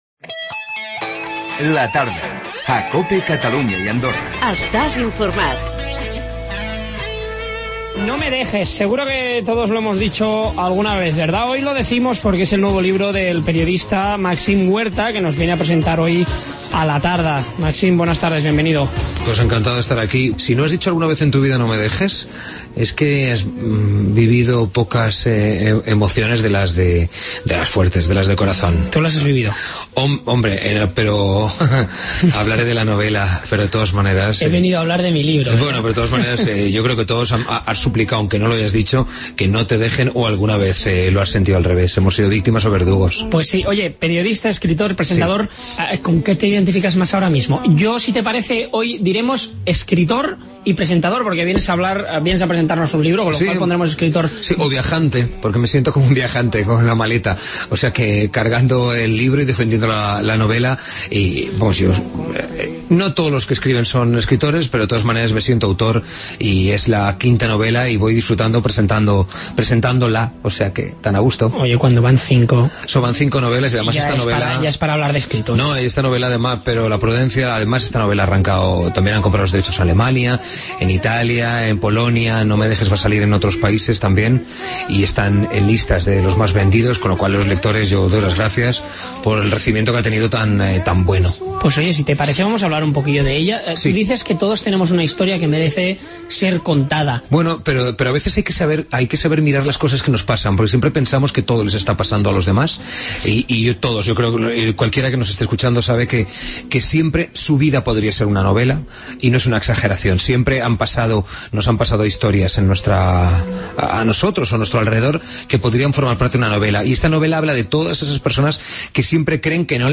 El periodista Màxim Huerta ens presenta a La Tarda el seu nou llibre 'No me dejes'